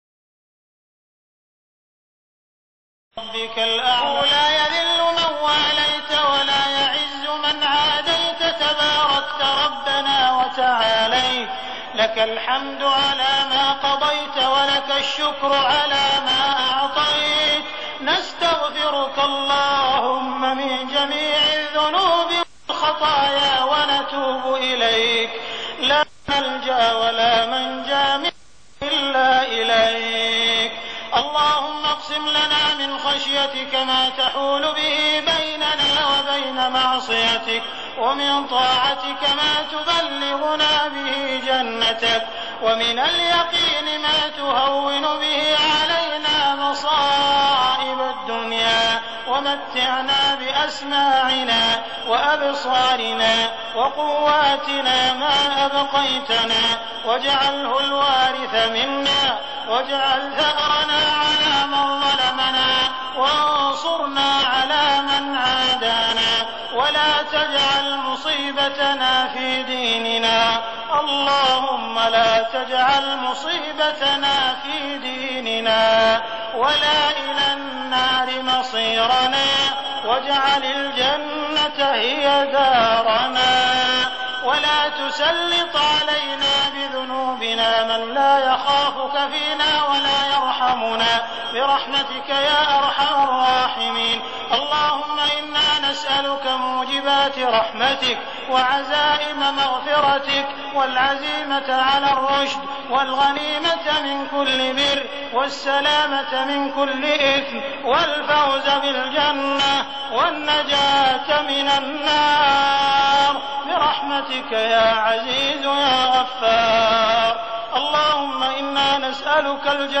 دعاء القنوت رمضان 1419هـ > تراويح الحرم المكي عام 1419 🕋 > التراويح - تلاوات الحرمين